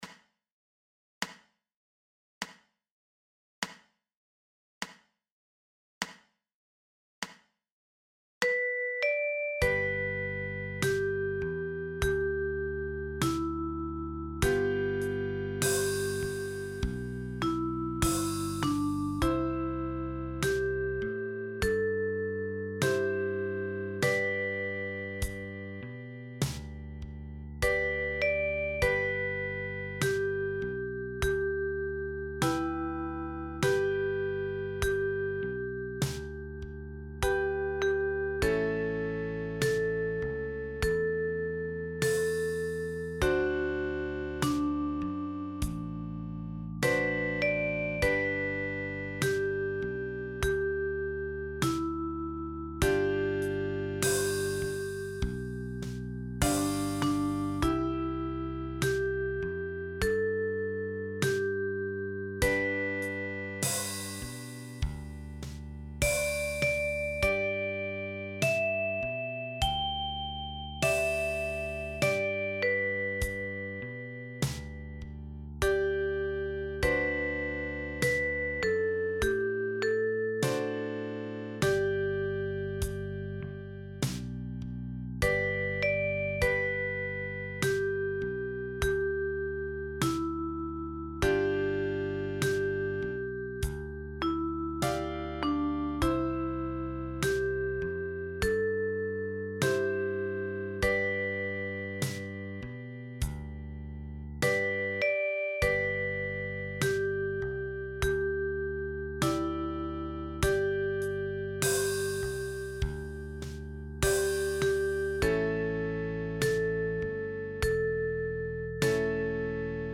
Liederbuch für die chromatische Mundharmonika